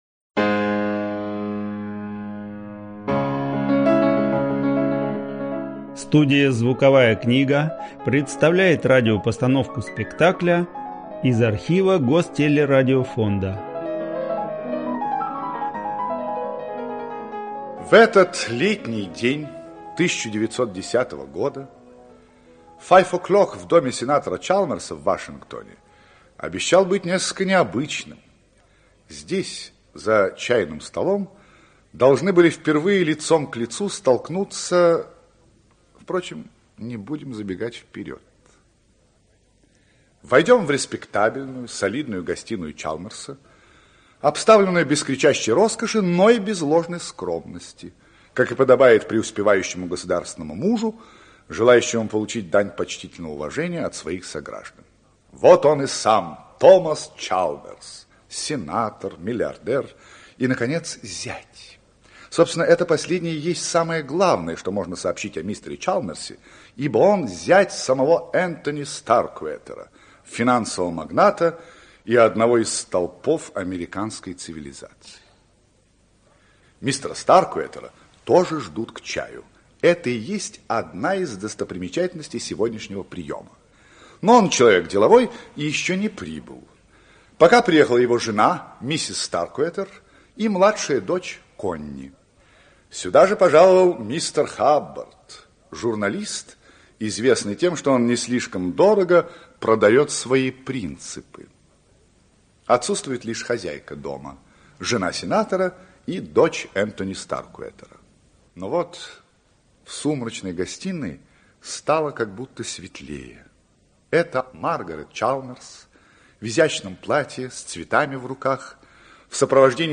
Аудиокнига Кража (спектакль) | Библиотека аудиокниг
Aудиокнига Кража (спектакль) Автор Джек Лондон Читает аудиокнигу Александр Леньков.